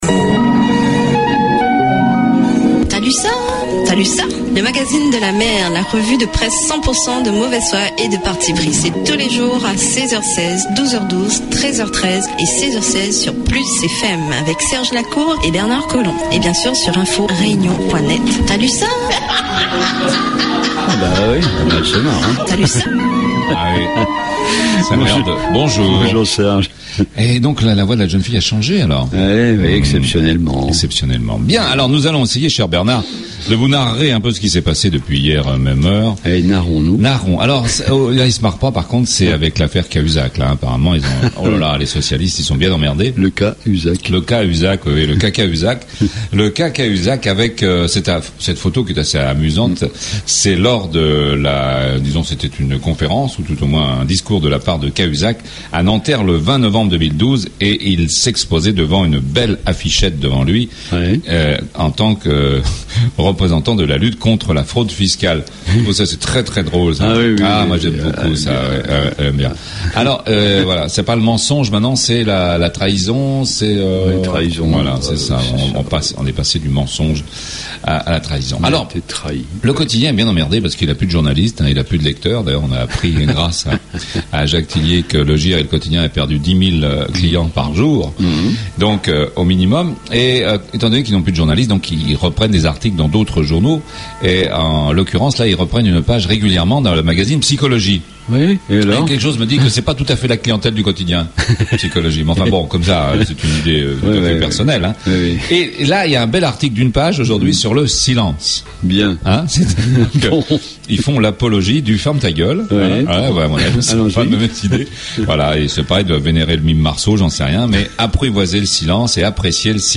REVUE DE PRESSE
...14 minutes d'Infos décalées sur PLUS FM ...